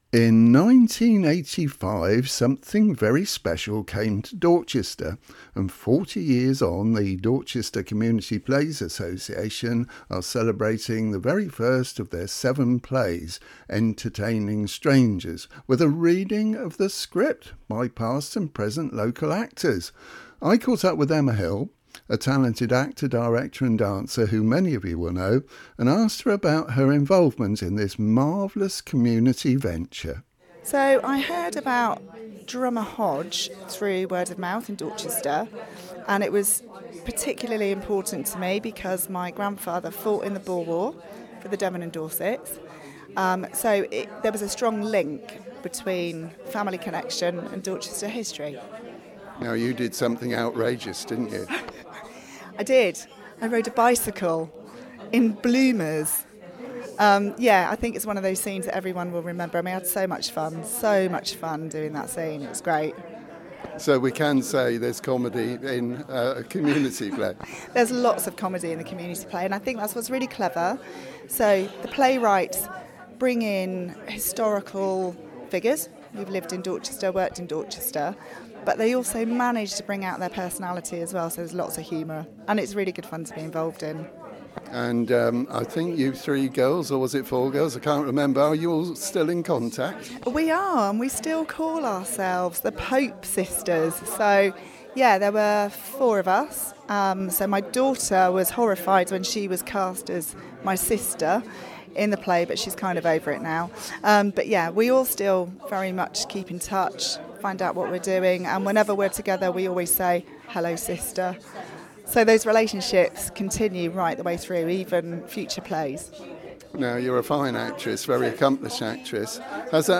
went along to the event and chatted to some of the actors…